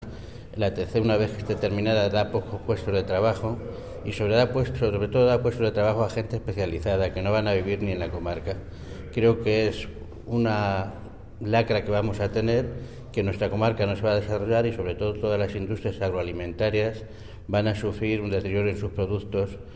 Cortes de audio de la rueda de prensa
Audio Alcalde Villamayor de Santiago
alcalde_Villamayor_de_Santiago.mp3